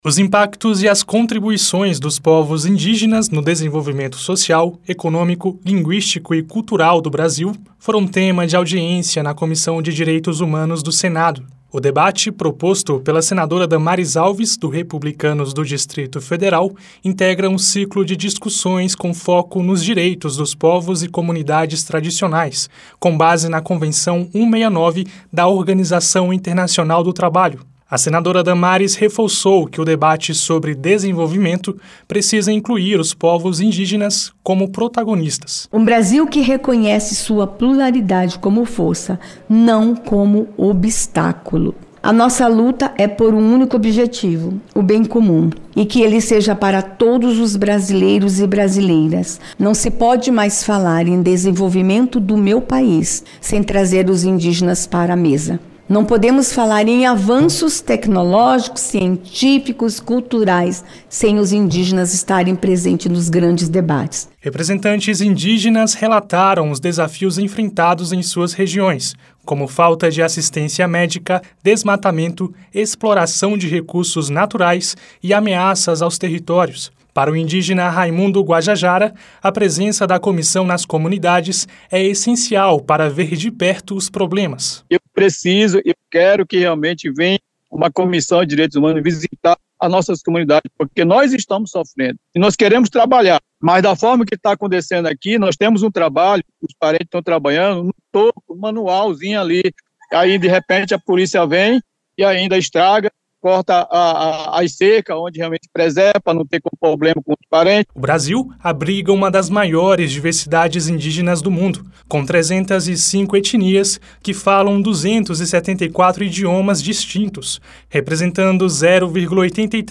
Para a senadora Damares Alves (Republicanos-DF), o debate sobre o desenvolvimento do país precisa incluir os povos indígenas como protagonistas. Ela fez a declaração nesta segunda-feira (14), durante audiência pública na Comissão de Direitos Humanos e Legislação Participativa (CDH), da qual é a presidente.